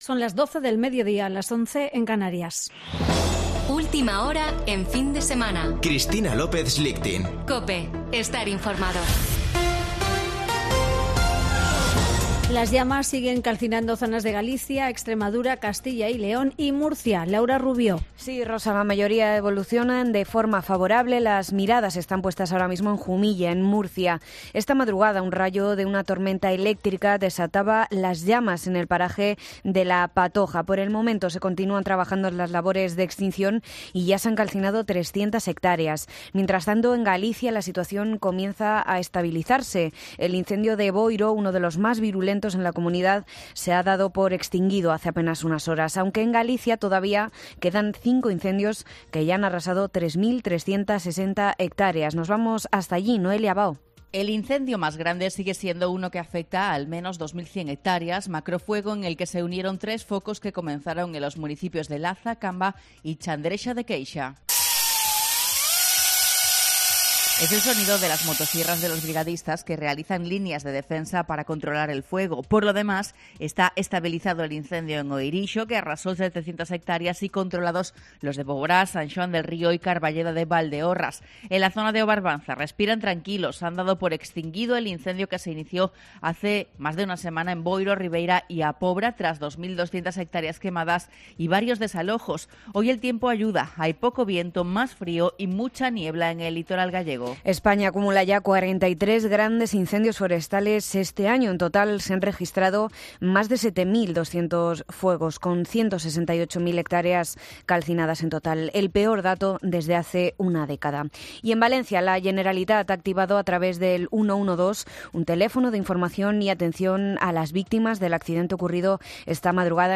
Boletín de noticias de COPE del 13 de agosto de 2022 a las 12.00 horas